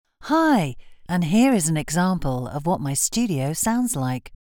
Female
British English (Native)
Approachable, Bubbly, Confident, Conversational, Friendly, Natural, Smooth, Upbeat, Witty
COMMERCIAL REEL.mp3
Microphone: Rode NT1A